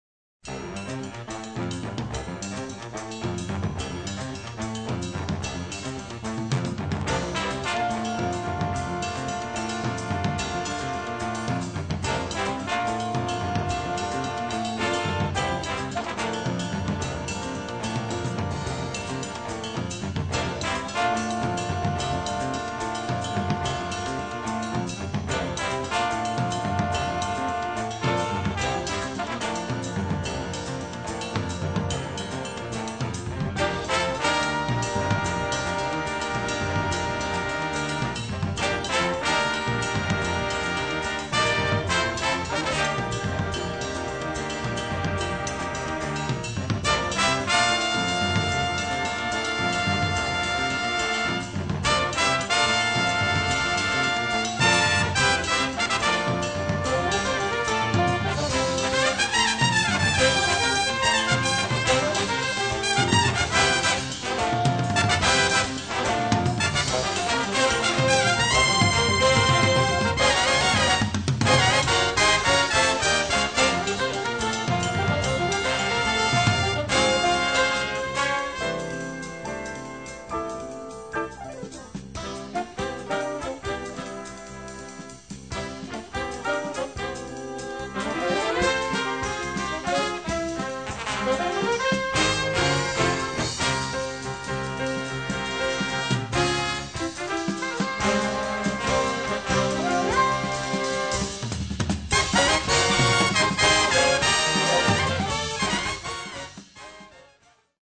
Big band jazz